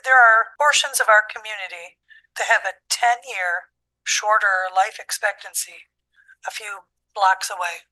Board chair Jen Strebs thinks the numbers tell the story.